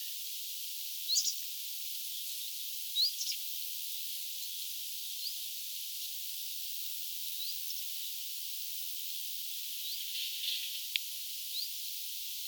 Kaksi vit-tiltalttia kuului tänään.
on vit-ääni kuin hyit-äänen lyhennelmä.
Hyit-ääni on kuin kaksiosainen, vit-ääni yksiosainen.